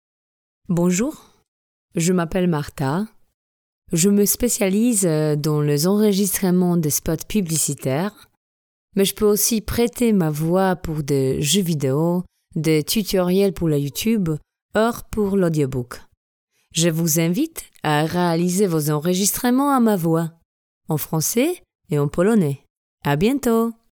Female 30-50 lat
Demo w języku francuskim